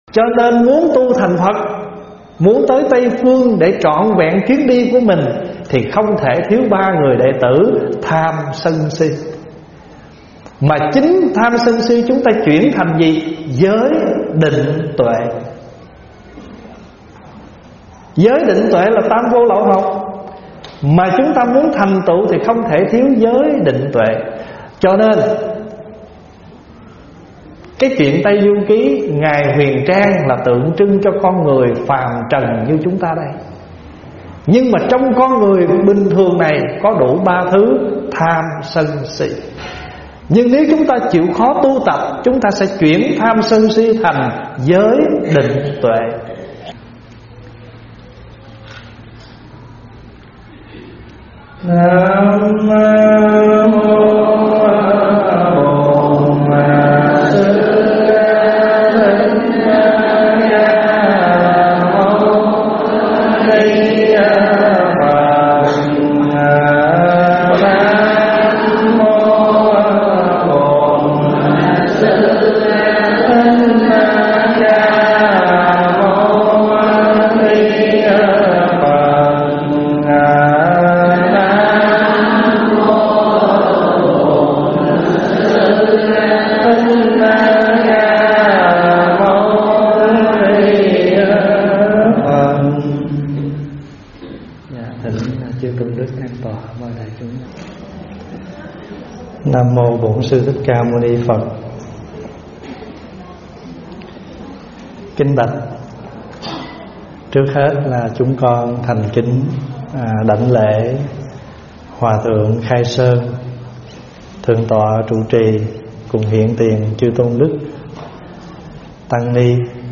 Nghe mp3 vấn đáp Tu Nhờ Tam Độc
tại Chùa Phước Huệ